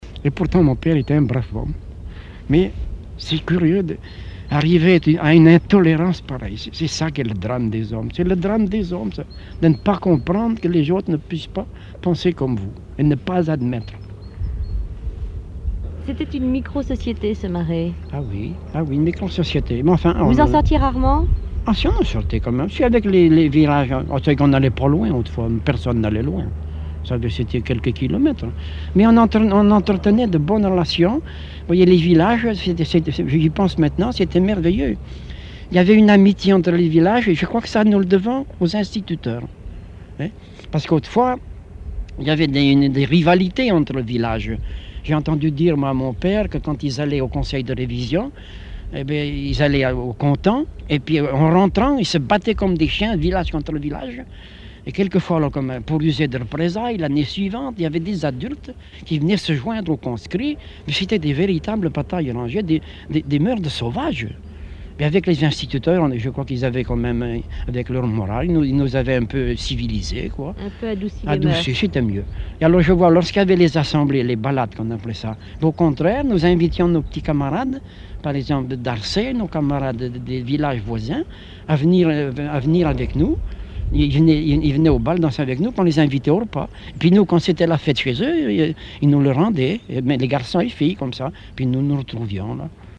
Emisson Départementale, sur Radio France Culture
Catégorie Témoignage